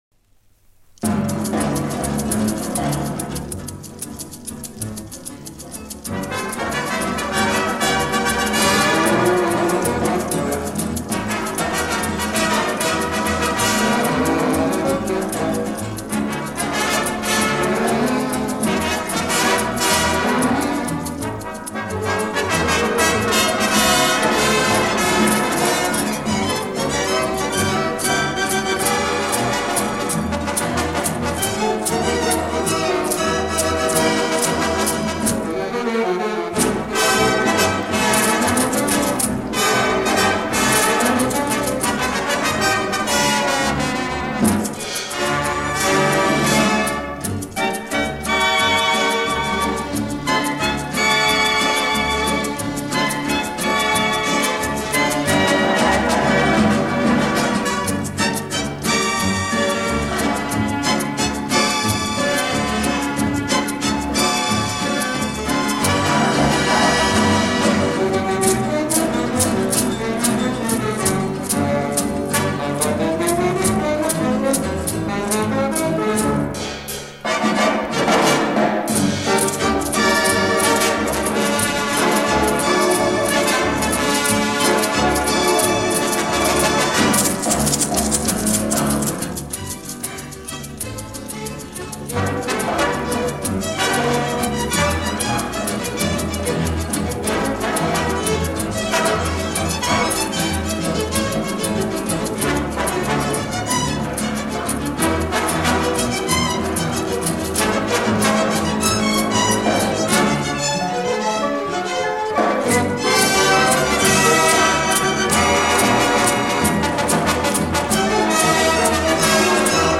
румба